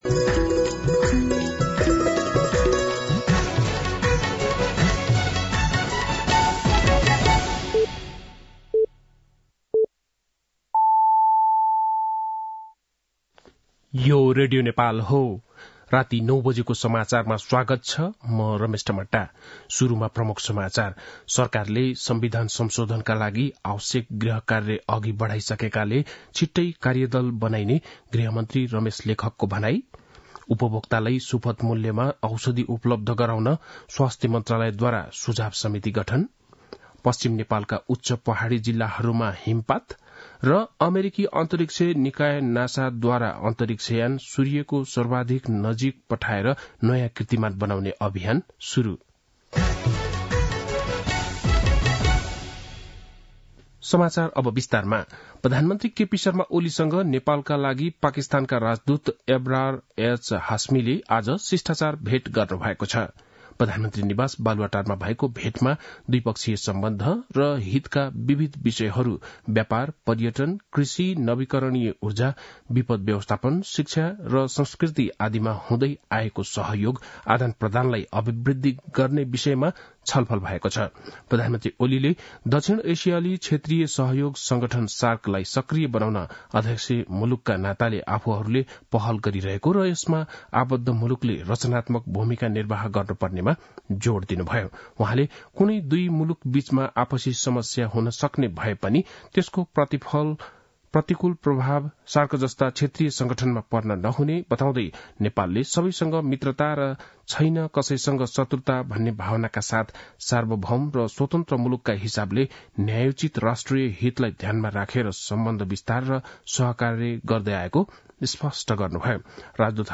बेलुकी ९ बजेको नेपाली समाचार : १० पुष , २०८१
9-PM-Nepali-NEWS-9-09.mp3